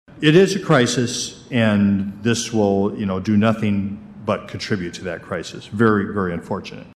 Mayor Dave Anderson says a voucher preference program targeting the homeless is also being slowly downsized, even though no new applications had been accepted for nearly a year anyway.